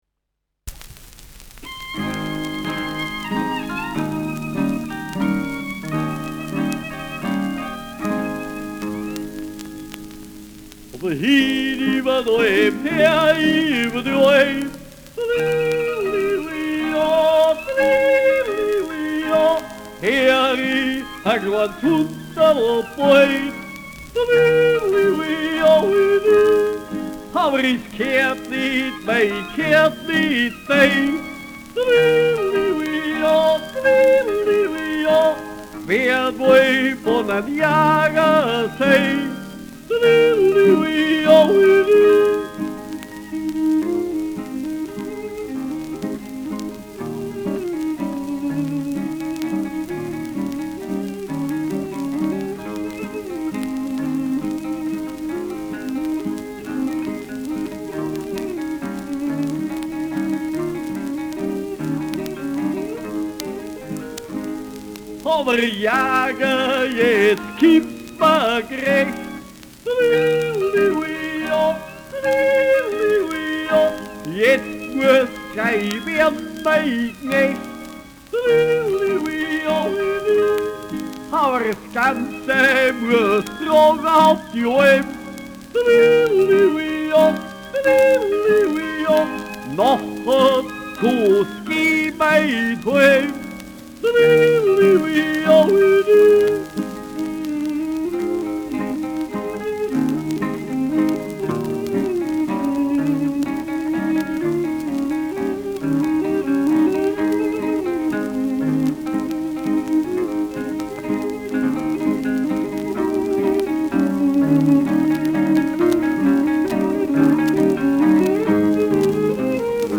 Schellackplatte
leichtes Knistern : leichtes Rauschen : leichtes Nadelgeräusch in zweiter Hälfte
Tegernseer Trio (Interpretation)
[München] (Aufnahmeort)
Stubenmusik* FVS-00016